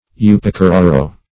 Search Result for " upokororo" : The Collaborative International Dictionary of English v.0.48: Upokororo \U`po*ko*ro"ro\, n. [From the native Maori name.]